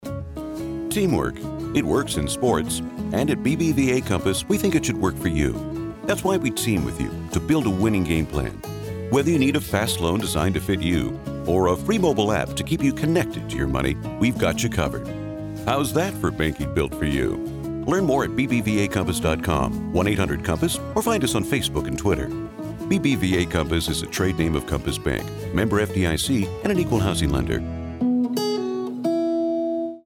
Affordable Professional Non Union Male Voiceover Talent
Link to hear Conversational new read for BBVA Commercial.